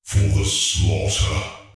Subject description: Some highly electronic hero unit voice resources!
These voices were self generated by me, and I carried out a series of complex follow-up work to make them highly electronic and magnetic.